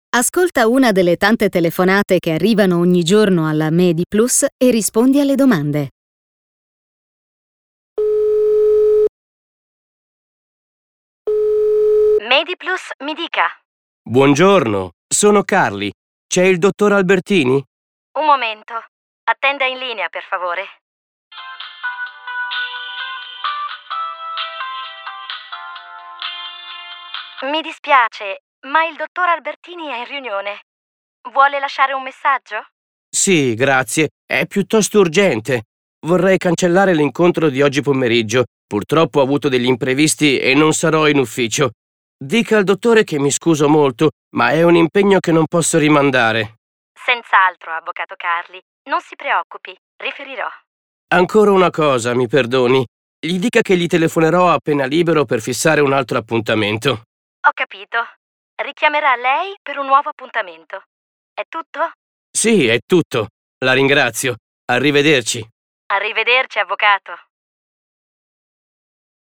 Telefonate (brani audio)